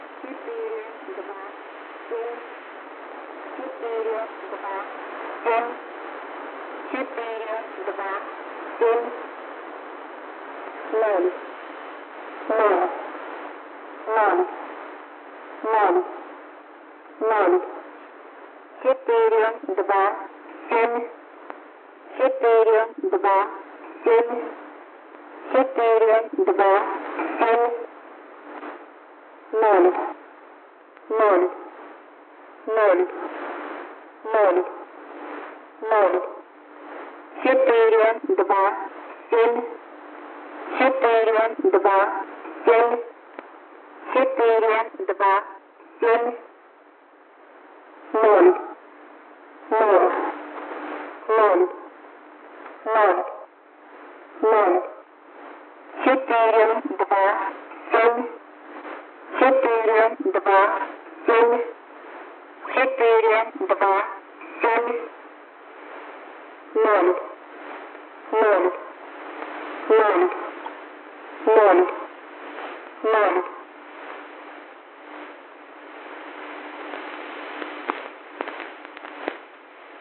radio_transmission_russian.ogg